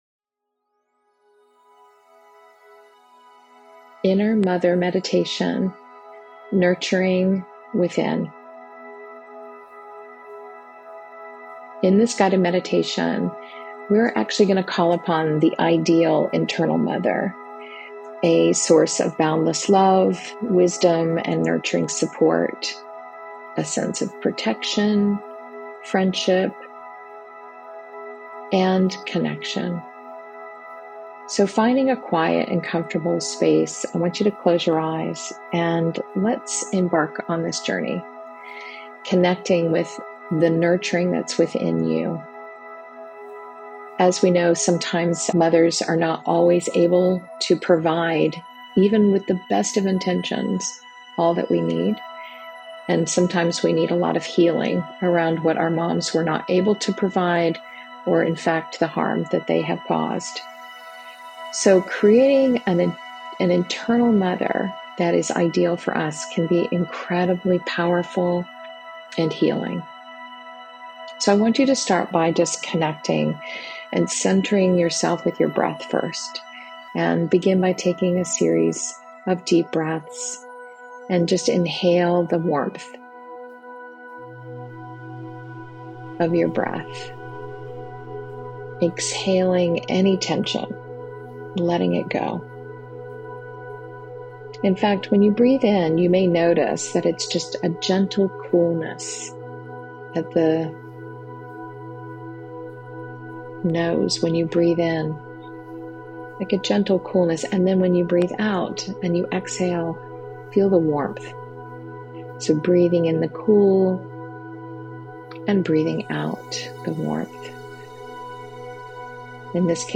This guided practice explores the strength found in vulnerability. We'll focus on the heart center, allowing ourselves to feel both strength and tenderness simultaneously.